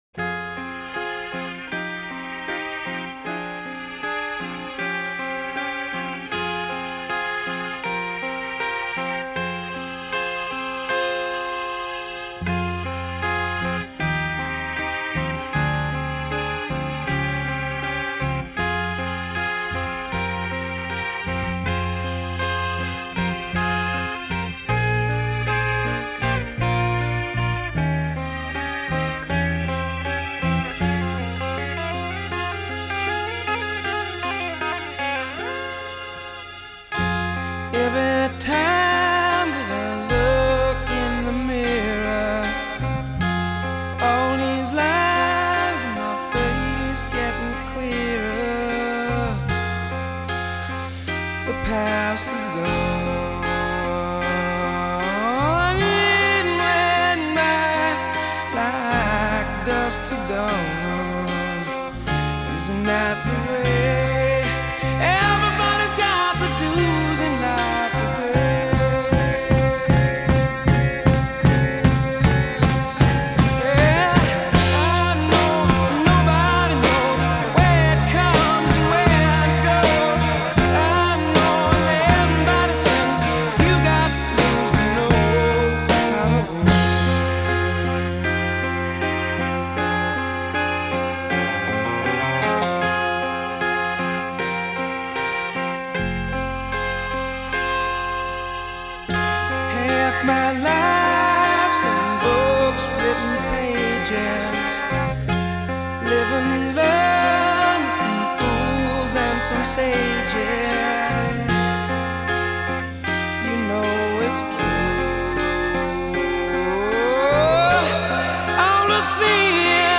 Classic Rock, Hard Rock